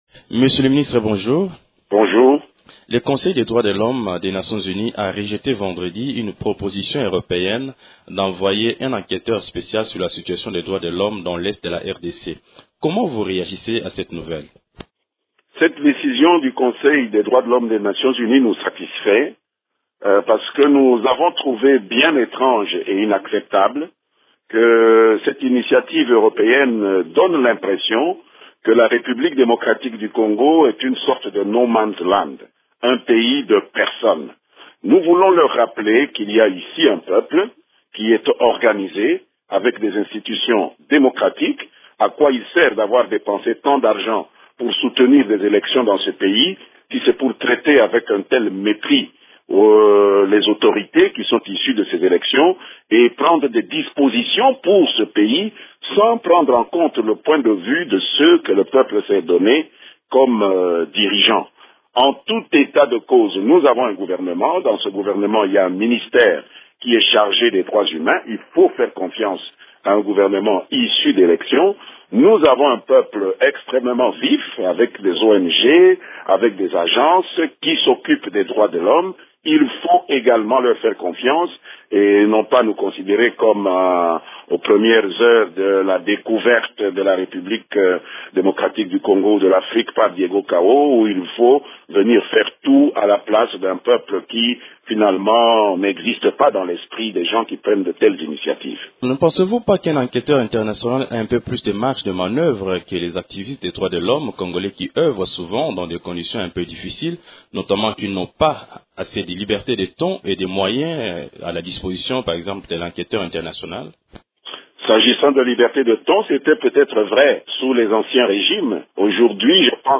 Réaction du gouvernement congolais avec Lambert Mende, ministre de la communication et médias et porte-parole du gouvernement